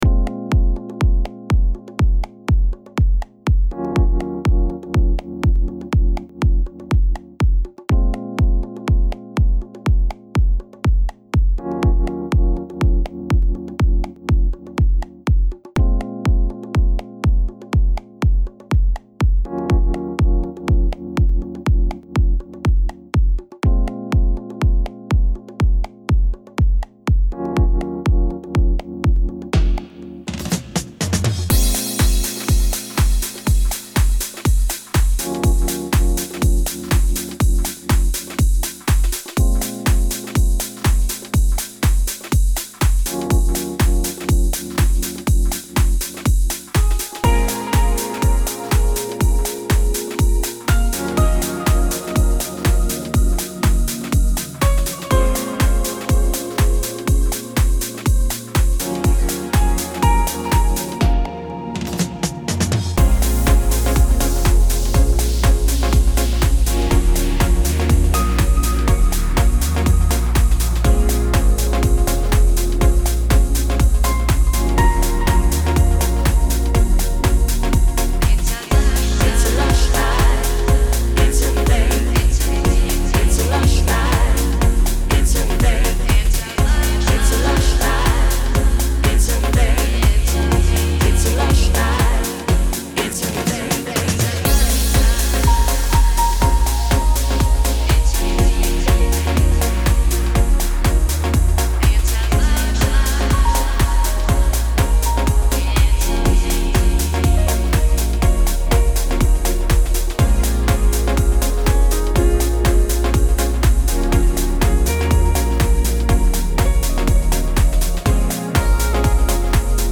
122 Soulful House